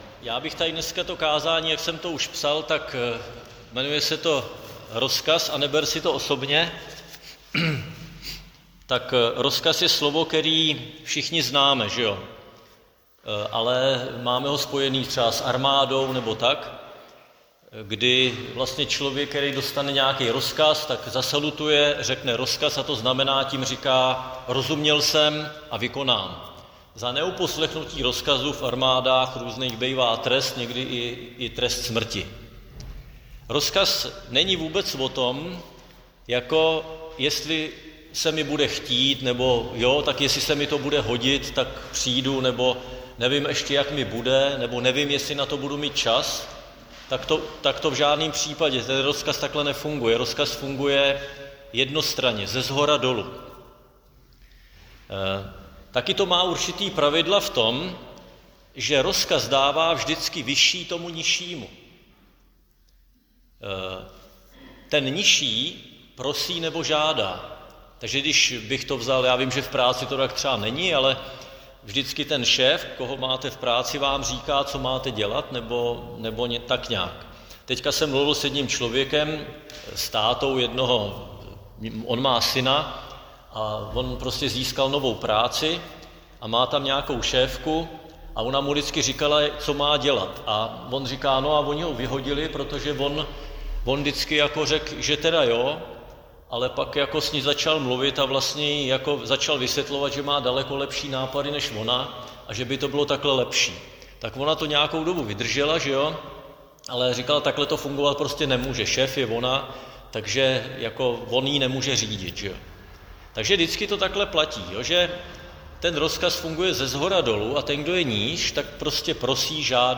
Křesťanské společenství Jičín - Kázání 20.7.2025